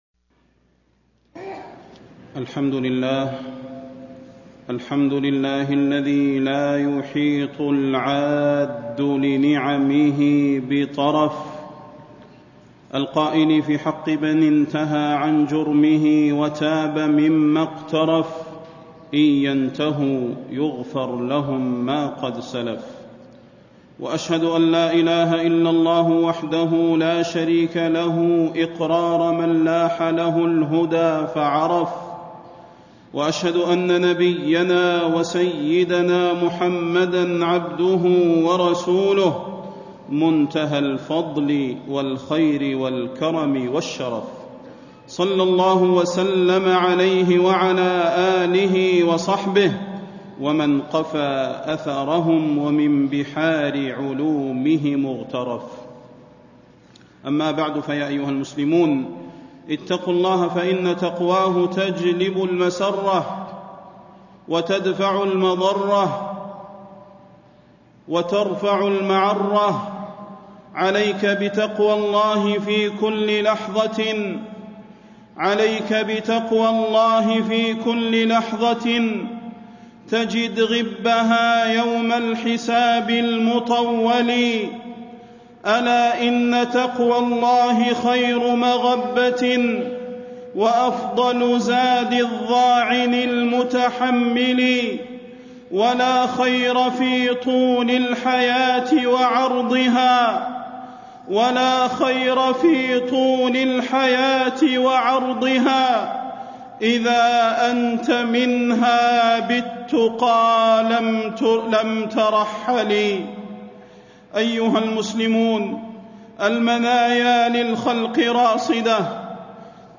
تاريخ النشر ٣ ذو القعدة ١٤٣٥ هـ المكان: المسجد النبوي الشيخ: فضيلة الشيخ د. صلاح بن محمد البدير فضيلة الشيخ د. صلاح بن محمد البدير التوبة قبل الممات